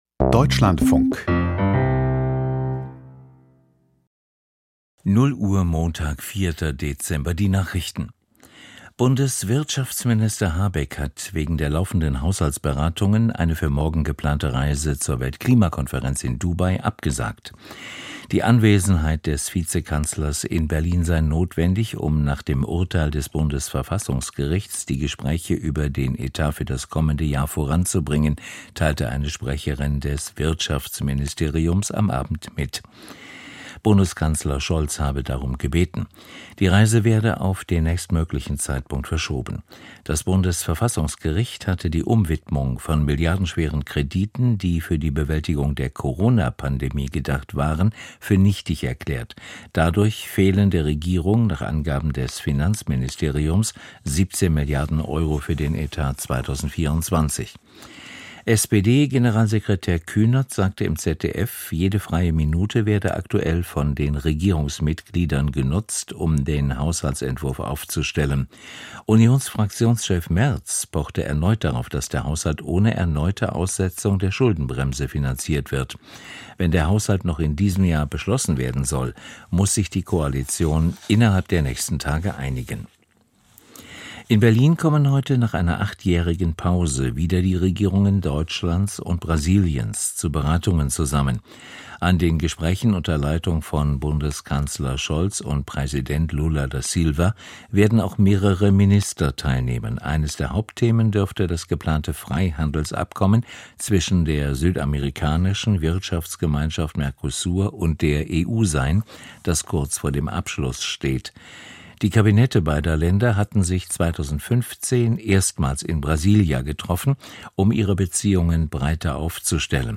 Die Nachrichten ➕ Prenumerera ➕ Prenumerera ✔ Prenumererar ✔ Prenumererat Spela Nu spelas Dela Markera alla som (o)spelade ...